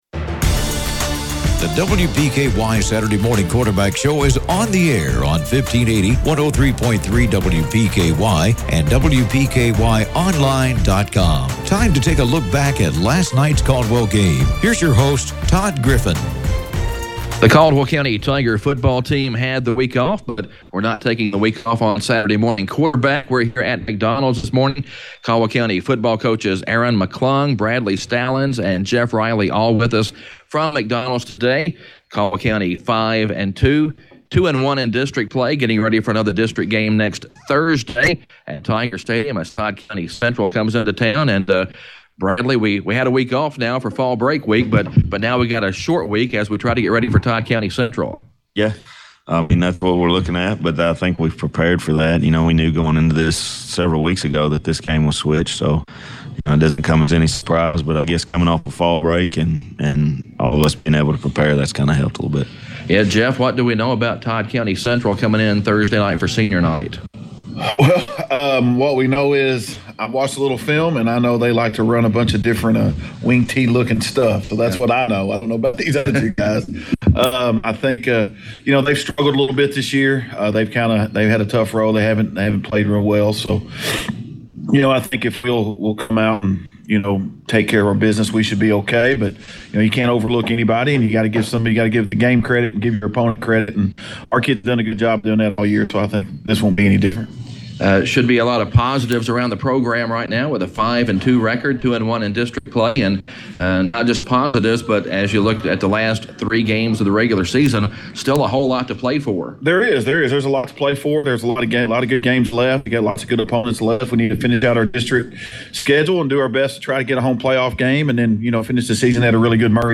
at McDonald’s in Princeton